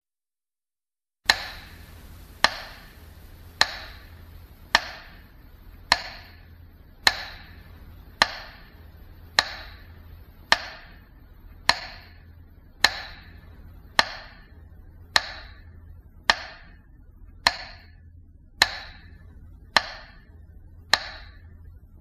Грустный звук памяти погибшим